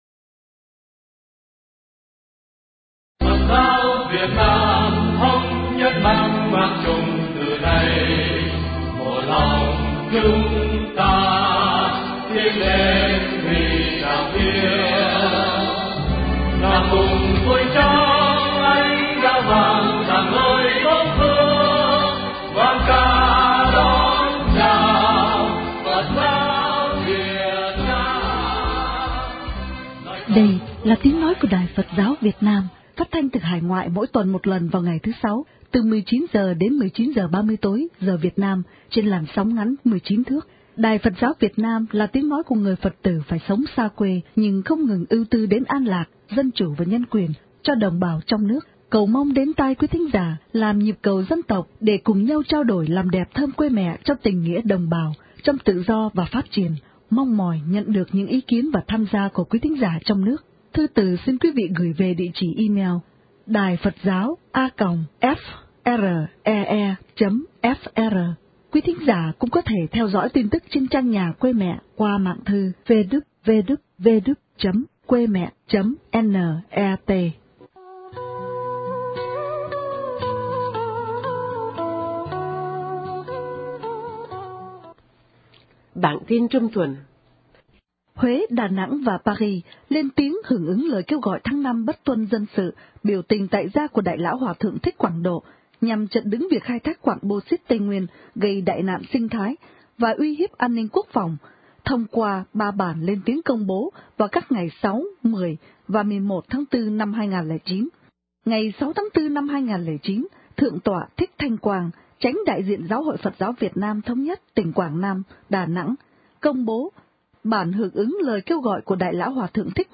phóng sự cuộc Hội luận Paris